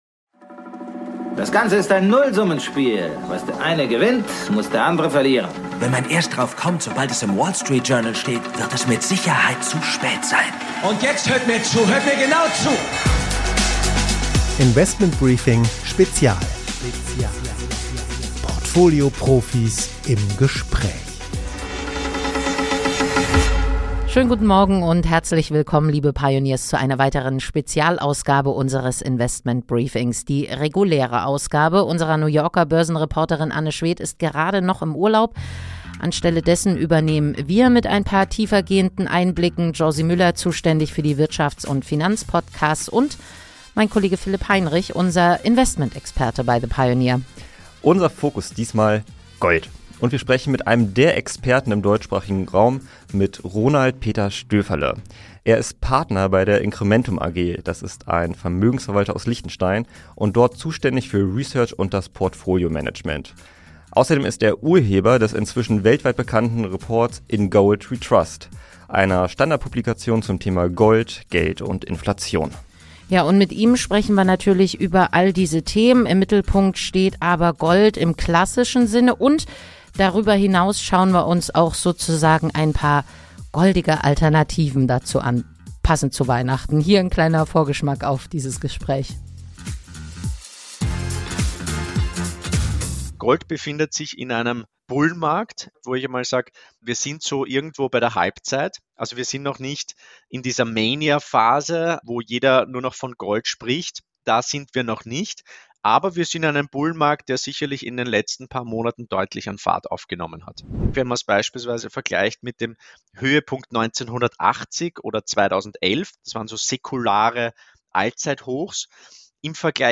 Im Interview spricht er über die Preisentwicklungen, über den Zusammenhang mit Schulden, Inflation und Zinsen, die Rolle der Schwellenländer, über Indien, China und die Vereinigten Arabischen Emirate.